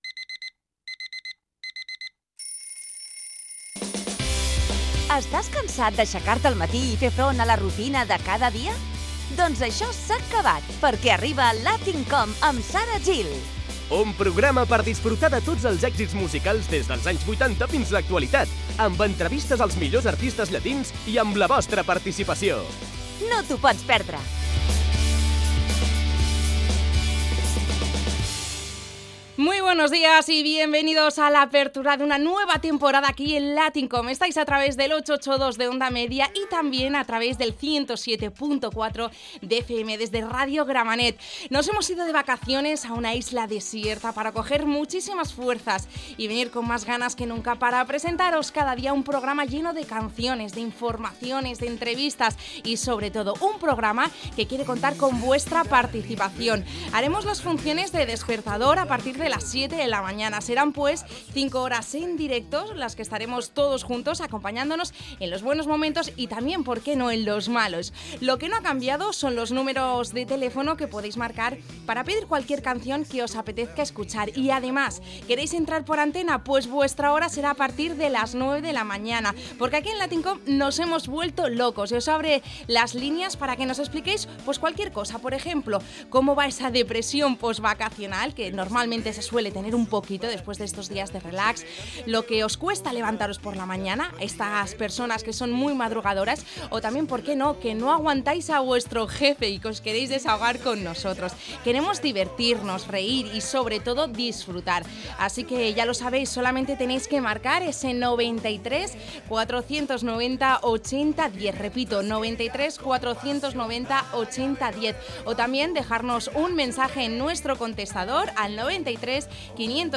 Entreteniment
Presentador/a
Fragment extret de l'arxiu sonor de COM Ràdio